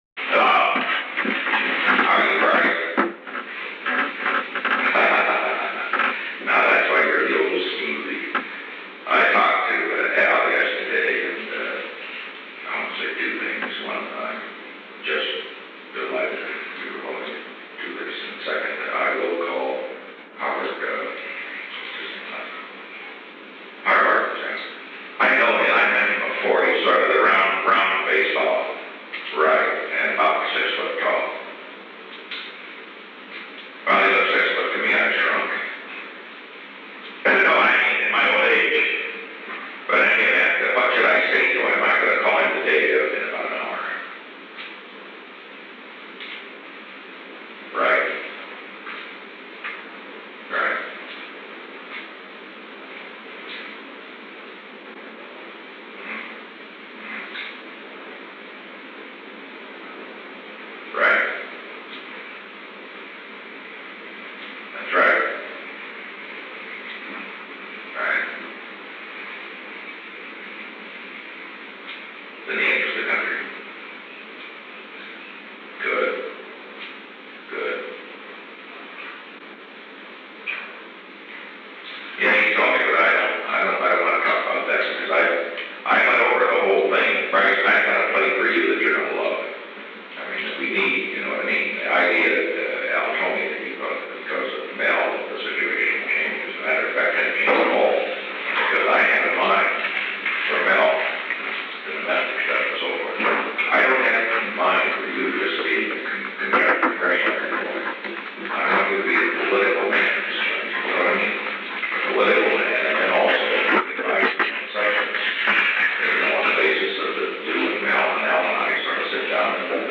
Conversation No. 937-5 Date: June 12, 1973 Time: Unknown between 9:19 am and 10:00 am Location: Oval Office The President talked with Bryce N. Harlow. [Conversation No. 937-5A] [Begin telephone conversation] [See Conversation No. 40-24] [End telephone conversation] Alexander M. Haig, Jr. entered at 9:25 am.
Secret White House Tapes